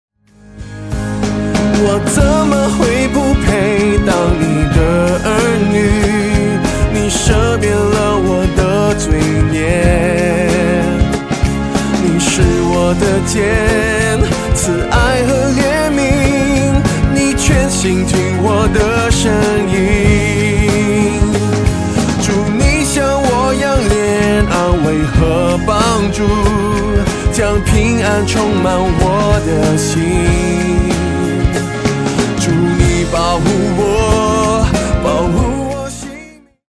Mandarin Worship Album